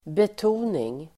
Uttal: [bet'o:ning]